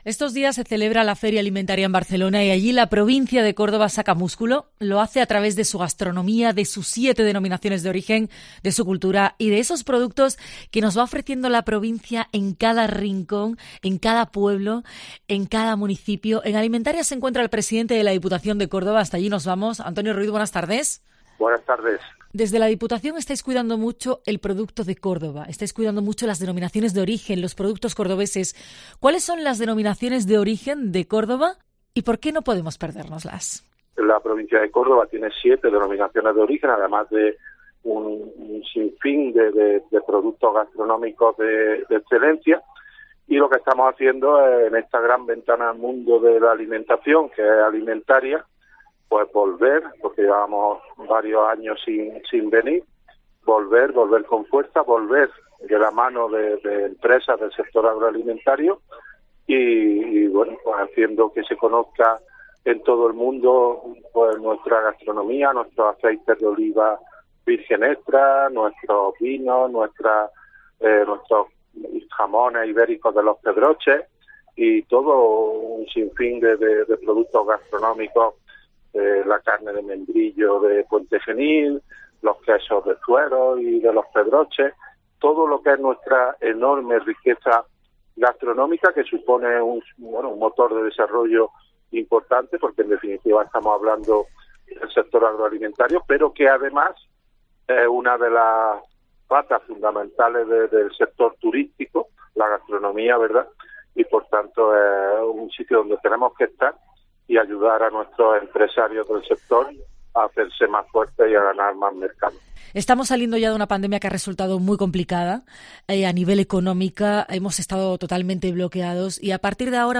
El presidente de la Diputación, Antonio Ruiz, habló desde Alimentaria 2022, Salón Internacional de la Alimentación, Bebidas y Food Service, para Cope Andalucía